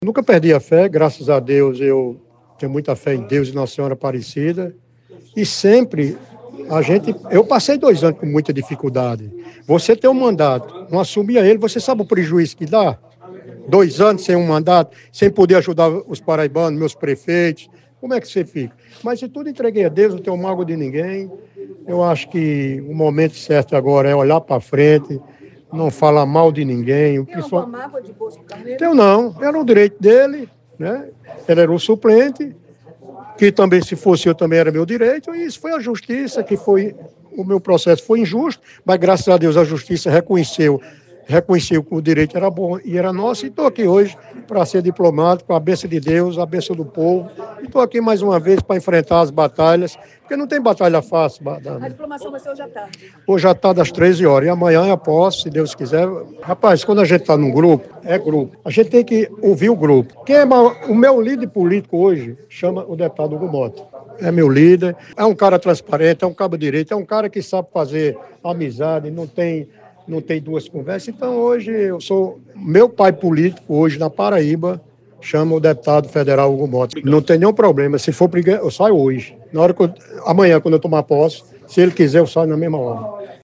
Os comentários de Márcio Roberto foram registrados pelo programa Correio Debate, da 98 FM, de João Pessoa, nesta segunda-feira (16/12).